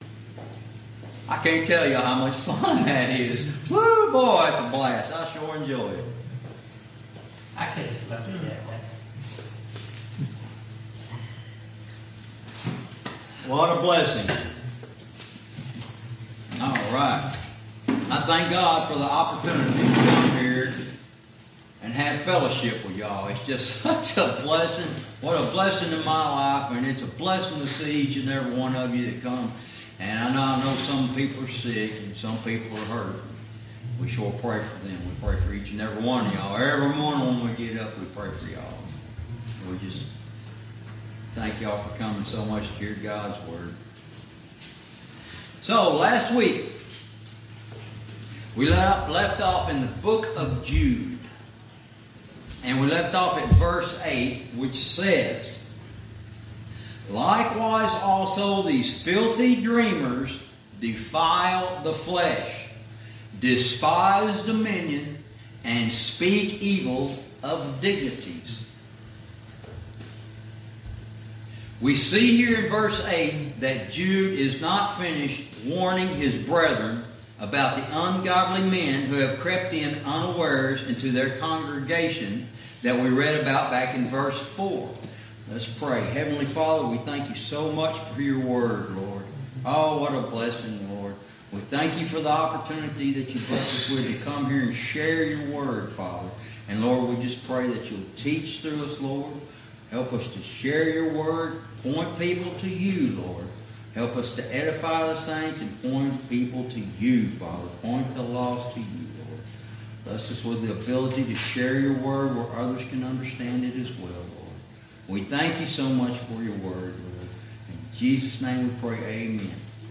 Verse by verse teaching - Lesson 23